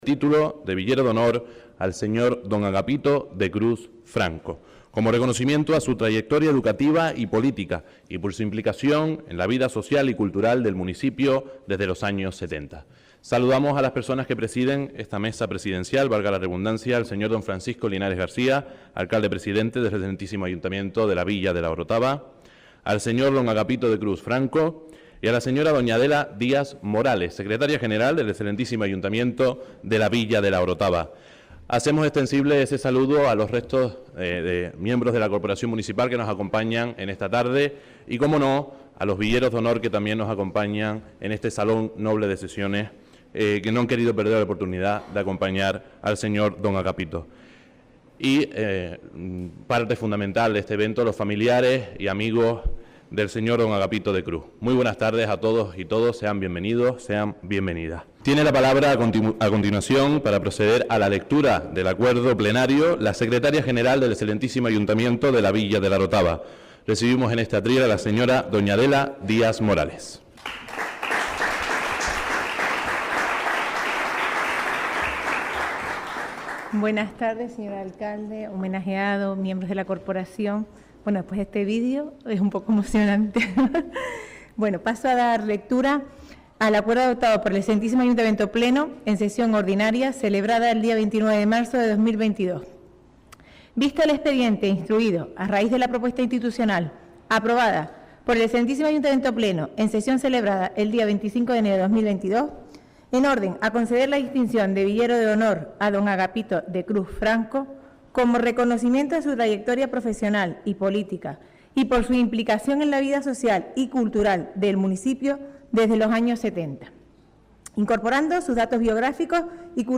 La Orotava. Acto de distinción de Villero de Honor